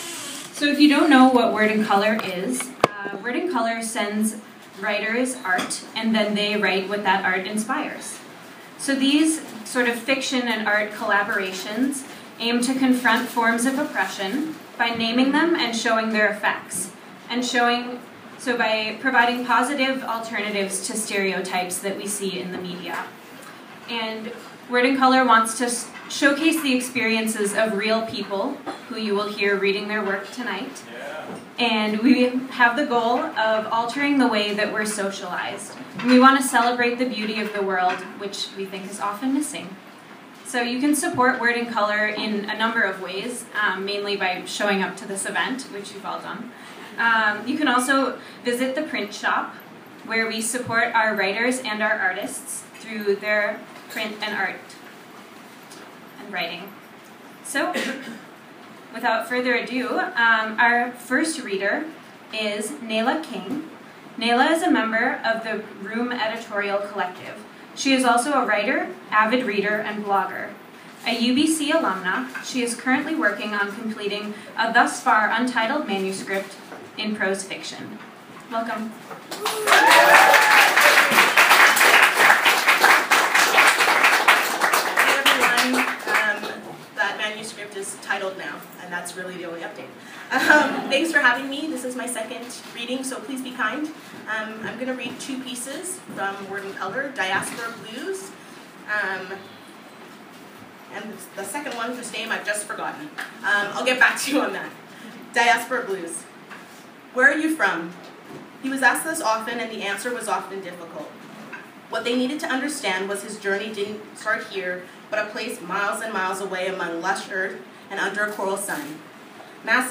word-and-colour-summer-reading-series.m4a